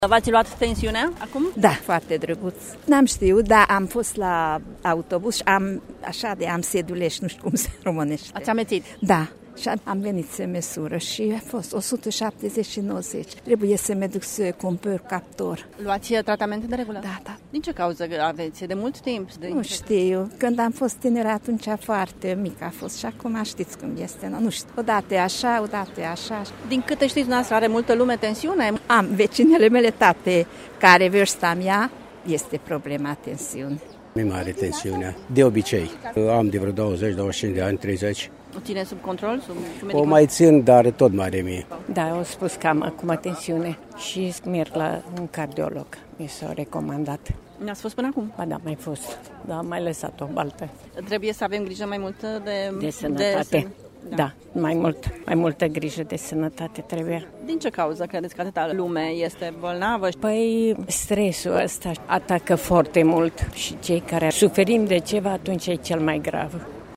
Medici cardiologi mureșeni din conducerea Societății Române de Hipertensiune, alături de voluntari, au organizat astăzi, în Piața Teatrului din Tg.Mureș, o acțiune de măsurare gratuită a tensiunii arteriale și i-au sfătuit pe târgumureșeni să scrie mesaje scurte din viață care să reamintească fiecăruia cât de importante sunt alegerile de azi pentru sănătatea de mâine.
Târgumureșenii s-au prezentat în număr mare la acțiunea de măsurare a tensiunii arteriale și aproape toți au fost găsiți hipertensivi: